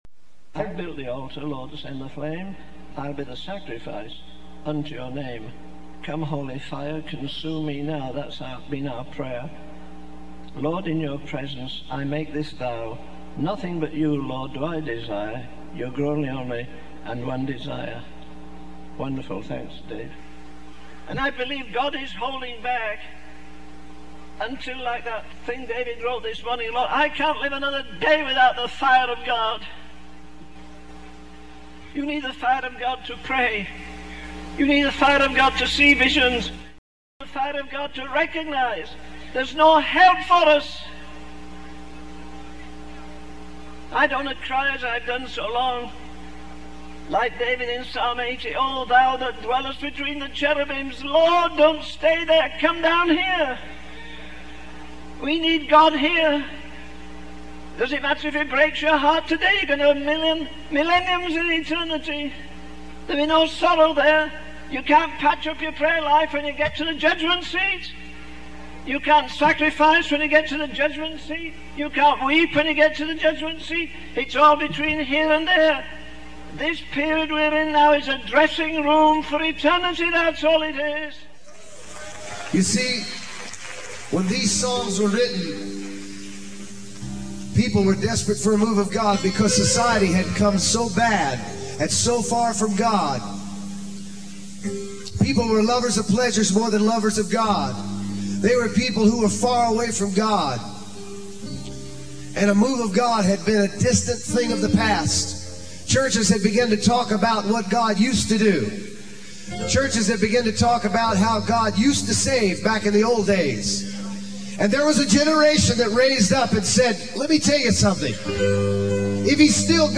In this sermon, the speaker reflects on the failure of the Hebridean revival and the importance of knowing how to handle and structure a move of God. He shares a conversation with an old man who emphasizes the need to hold on to God when we encounter Him.